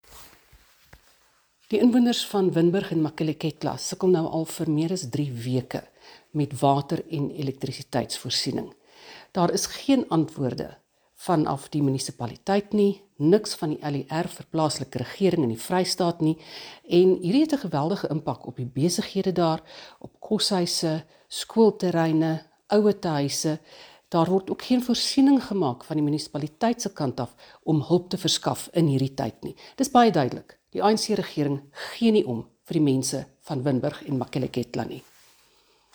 Afrikaans soundbites by Annelie Lotriet MP.